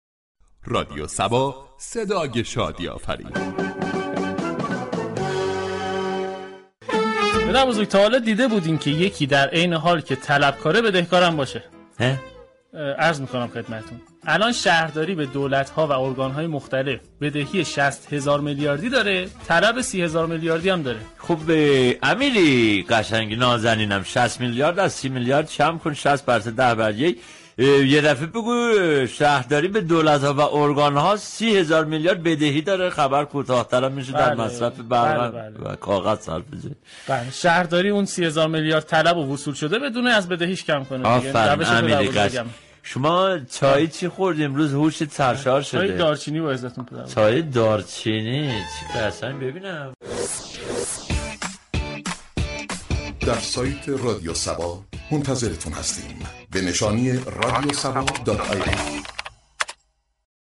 صبح صبا كه هر روز درساعت 07:00 صبح با پرداختن به موضوعات و اخبار روز جامعه ،لبخند و شادی را تقدیم مخاطبان می كند در بخش خبری با بیان طنز به خبر بدهی شهرداری به دولت و ارگان‌های مختلف پرداخت.